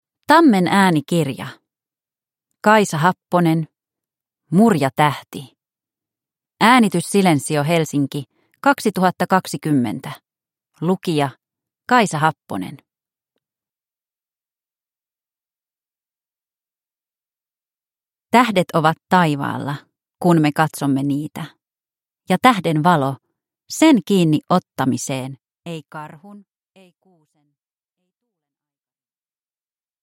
Mur ja tähti – Ljudbok – Laddas ner